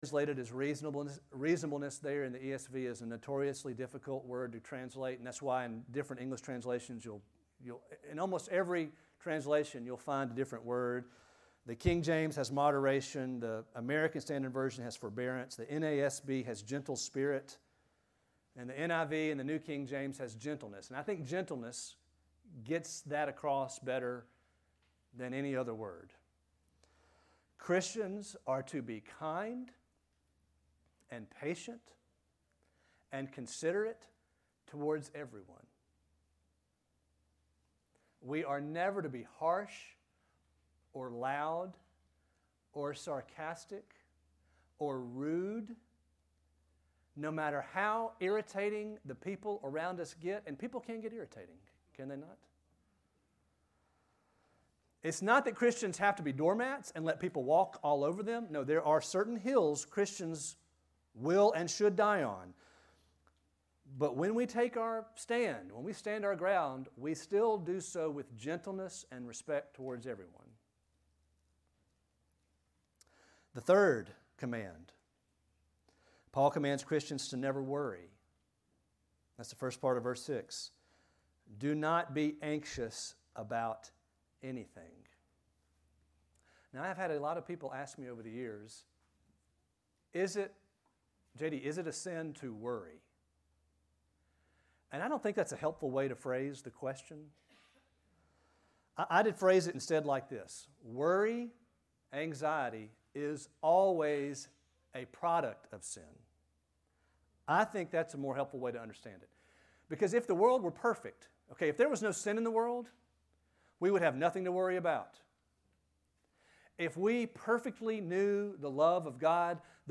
Sermons | Grace Bible Church of Oxford